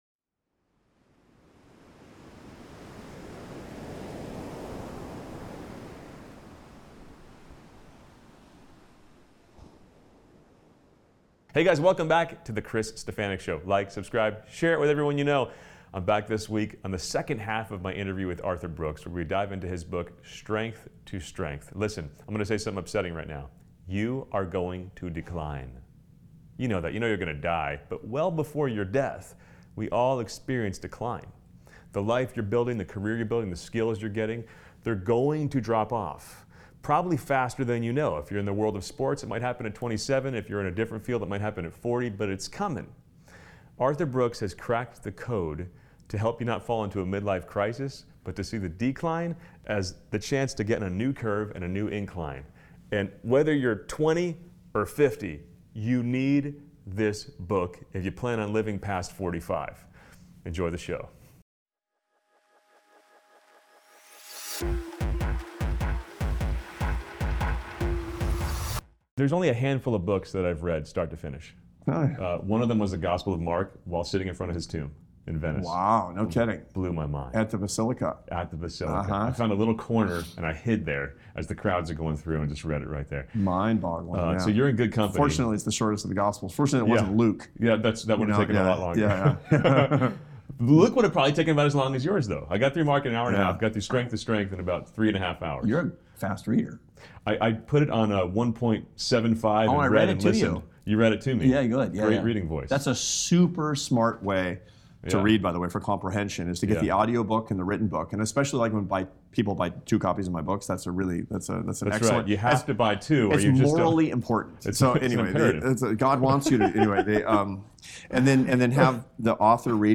SPECIAL THANKS TO CORPORATE TRAVEL, PRODUCERS OF THE GOOD NEWS CONFERENCE for making this interview possible.